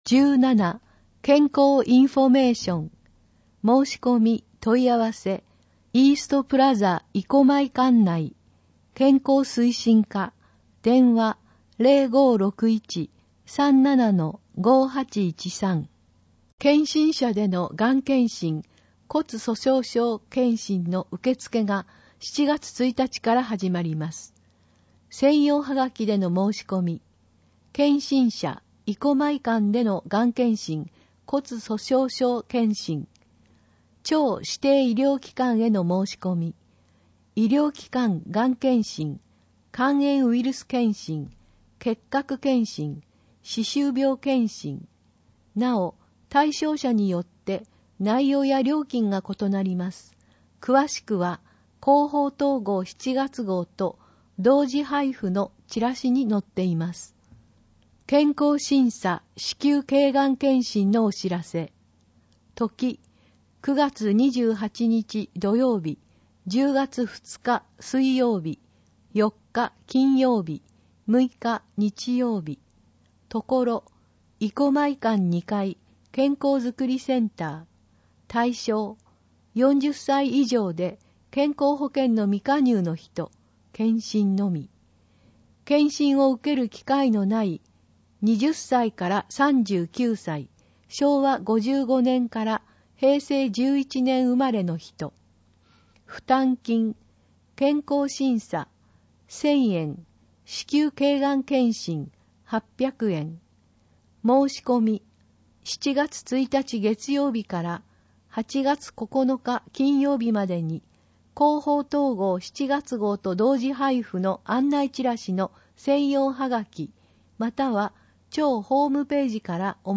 広報とうごう音訳版（2019年7月号）